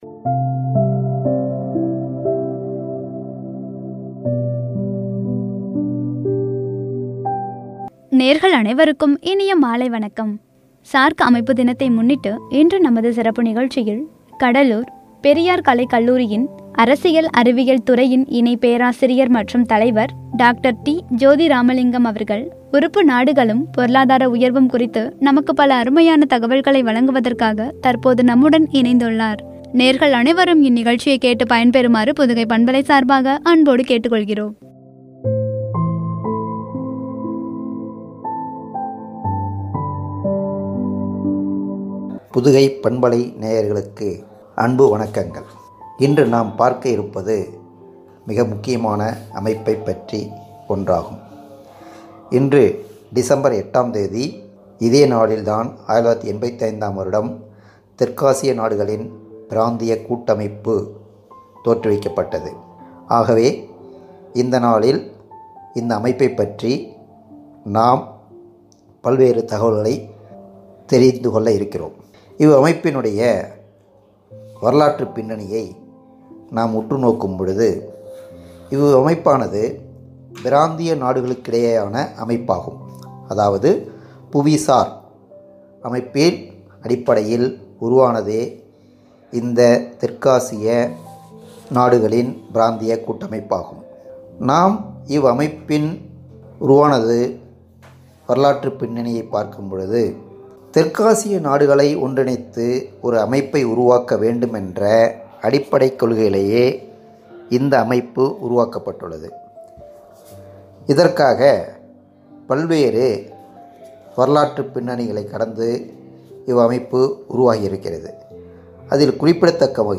பொருளாதார உயர்வும்” என்ற தலைப்பில் வழங்கிய உரை.